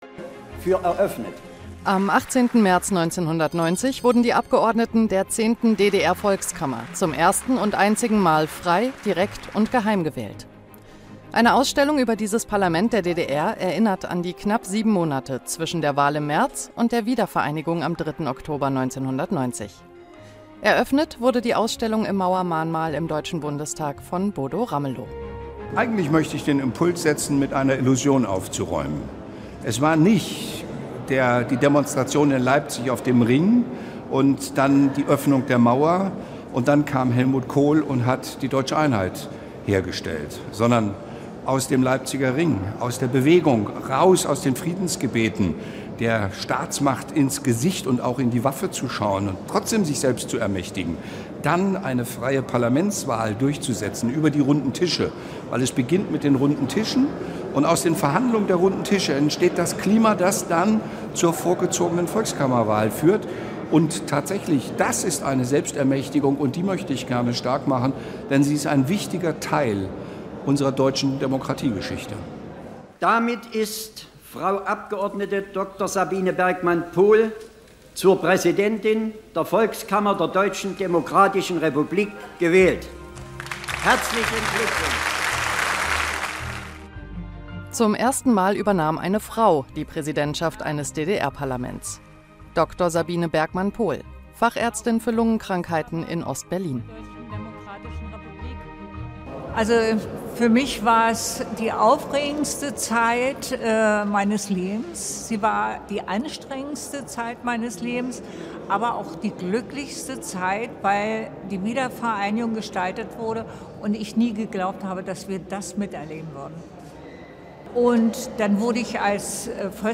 Ausstellungseröffnung - Das Volk ist sich seiner selbst bewusst geworden.